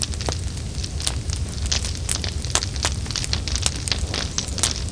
burning1.mp3